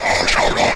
spawners_mobs_mummy_spell.ogg